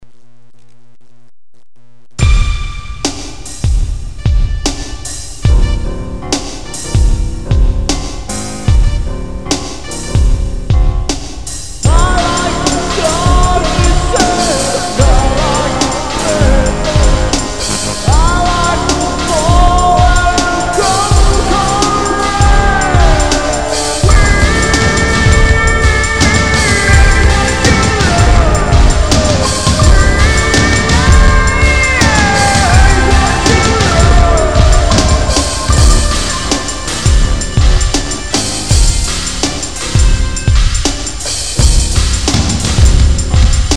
Guitars
Synth
Drums
Bass
Vocals